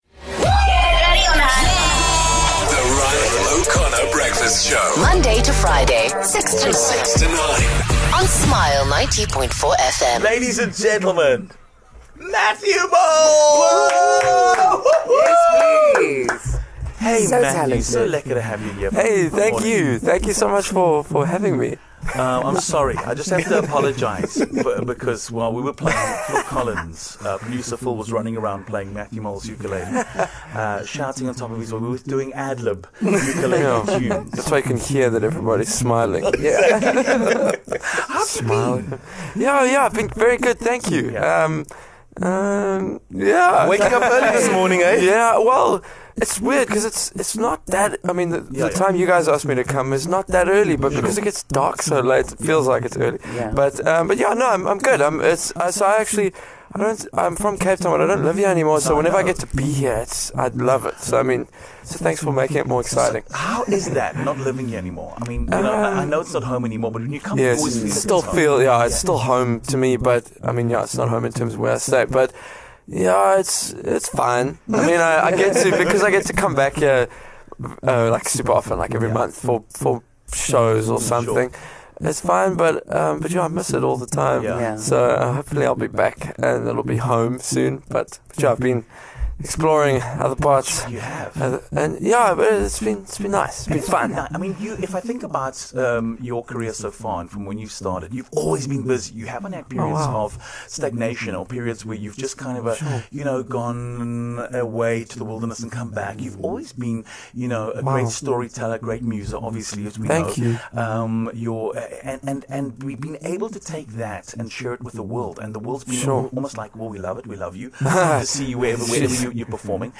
Visiting Cape Town for a few days, Matthew popped into studio to talk about what he's working on right now.